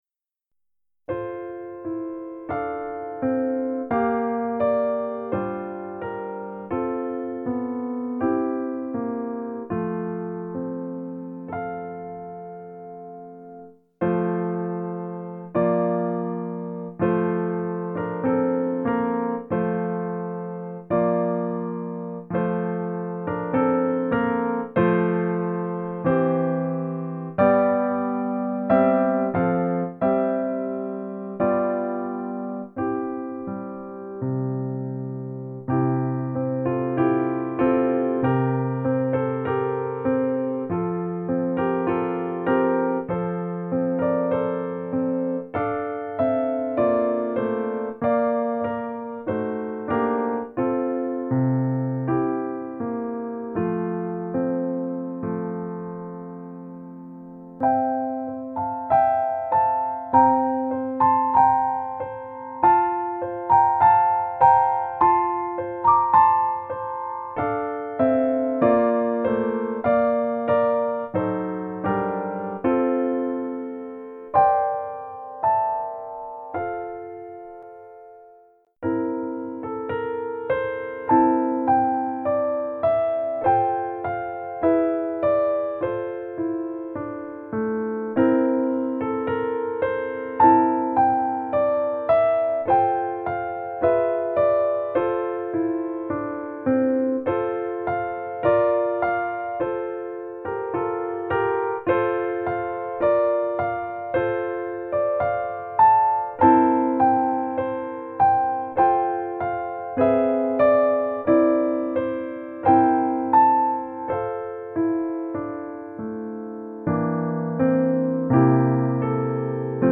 Weihnachtslieder
Oh du fröhliche (instrumental)
oh-you-joyful-o-du-frohliche-weihnachtszeit-carol-piano-1800.mp3